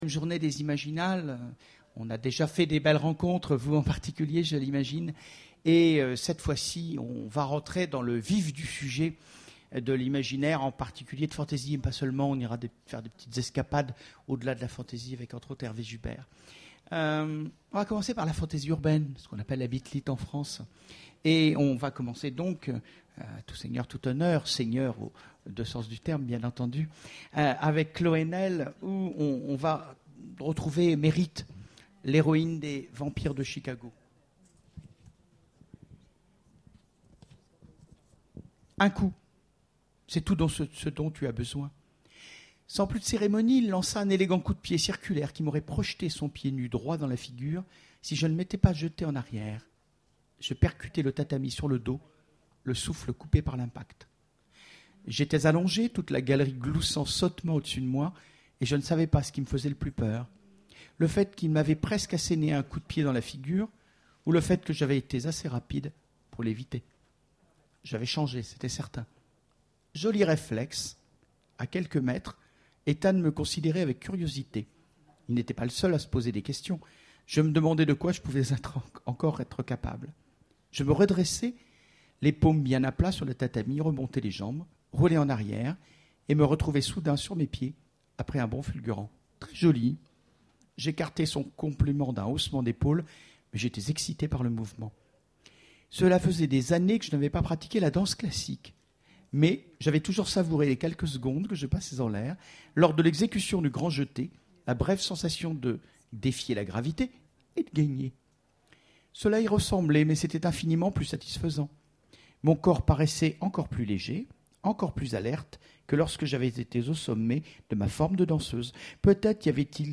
Imaginales 2014 : Conférence La baston j'aime ça !